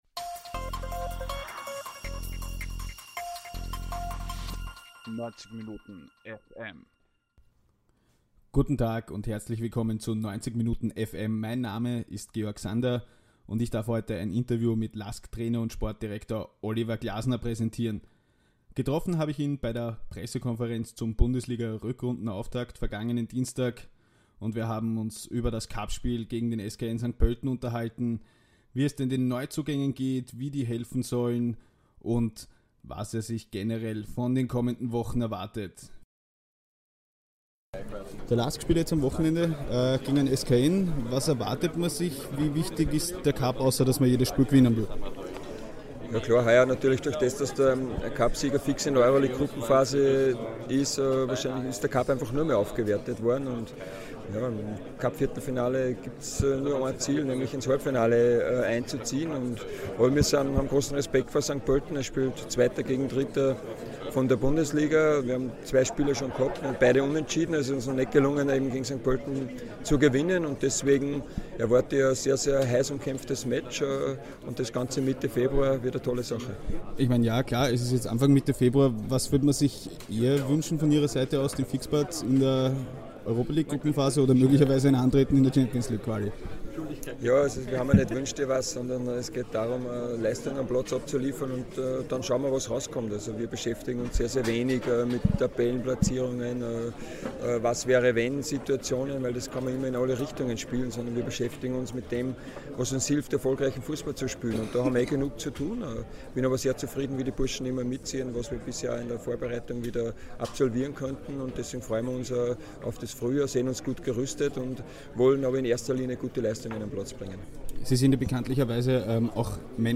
Im Zuge der Bundesliga-Pressekonferenz beantworte Oliver Glasner